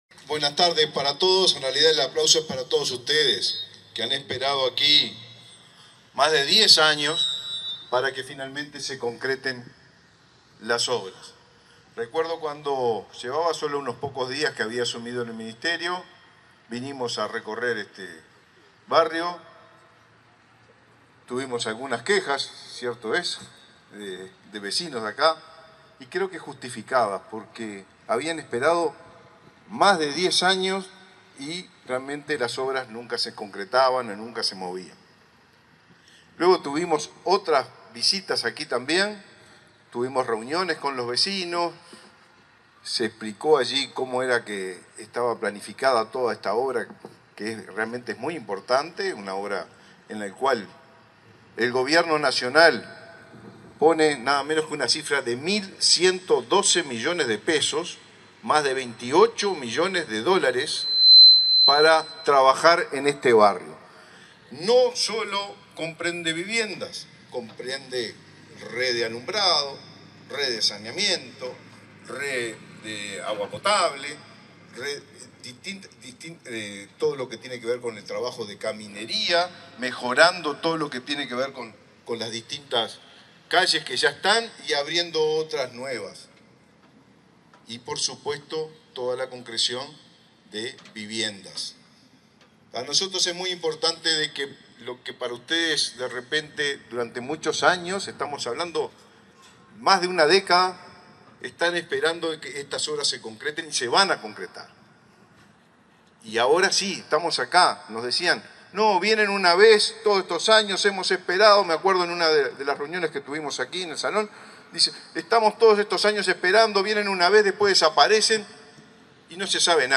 Palabras del ministro del MVOT, Raúl Lozano, en el barrio Maracaná Sur
Palabras del ministro del MVOT, Raúl Lozano, en el barrio Maracaná Sur 08/12/2023 Compartir Facebook X Copiar enlace WhatsApp LinkedIn El Ministerio de Vivienda y Ordenamiento Territorial (MVOT) iniciará la construcción de 200 soluciones habitacionales en el barrio Maracaná Sur, de Montevideo, en el marco del Plan Avanzar. En el acto, realizado este 8 de diciembre, disertó el ministro Raúl Lozano.